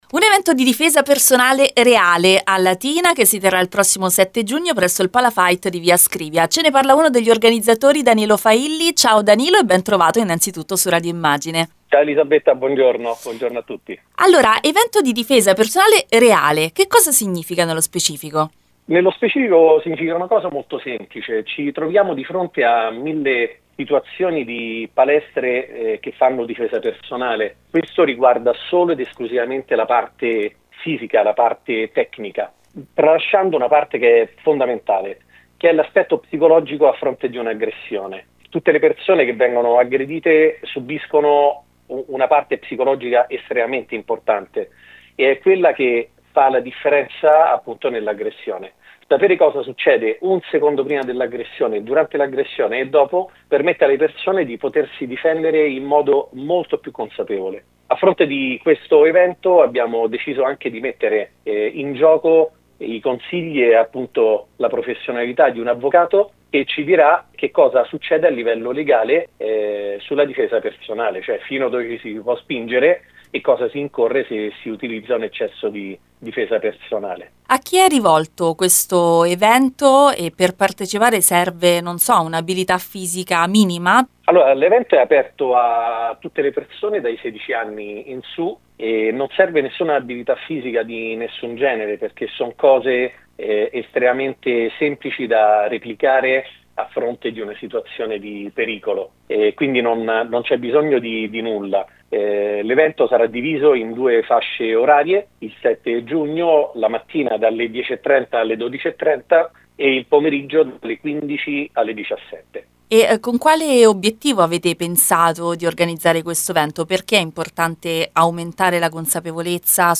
come ha spiegato uno degli organizzatori